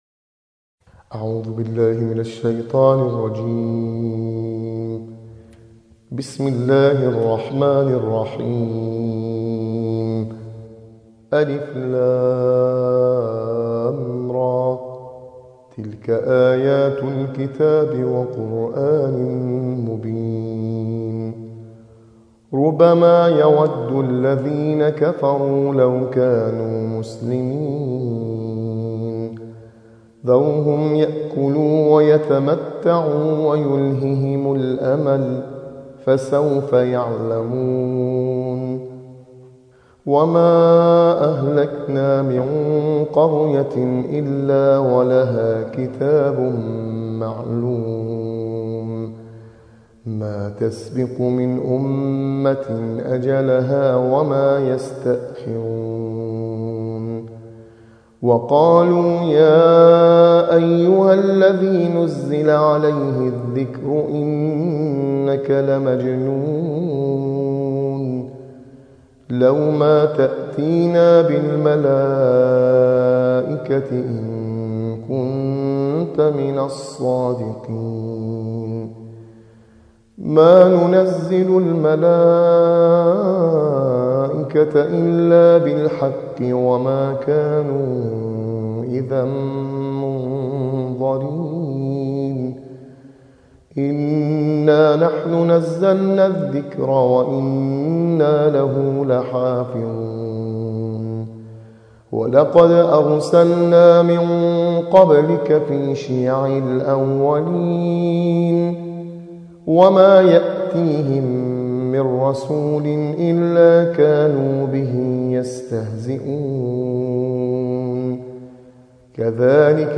صوت | ترتیل‌خوانی جزء چهاردهم قرآن + آموزش نغمات